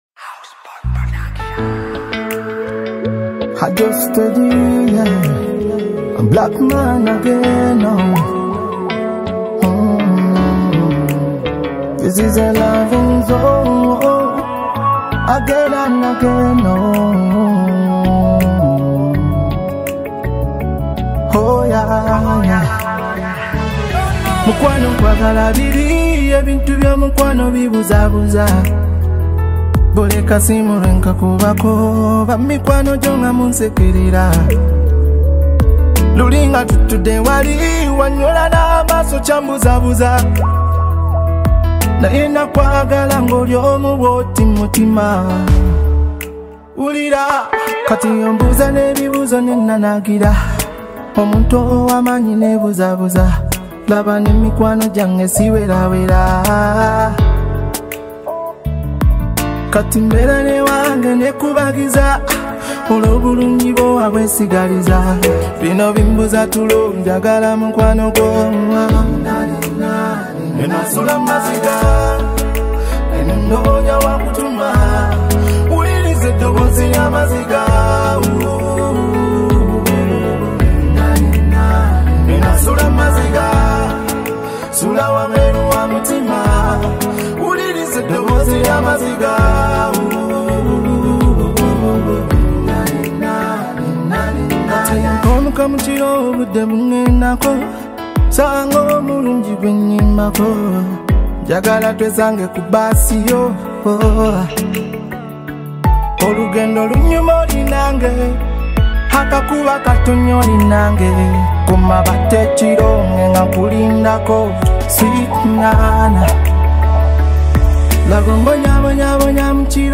Uganda singer-songwriter and music star